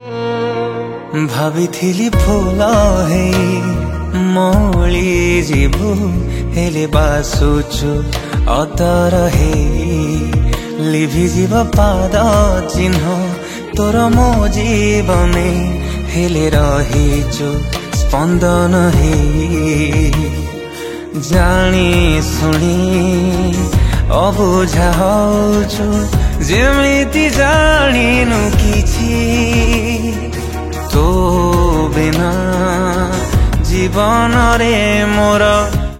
Sad  song
Odia ringtone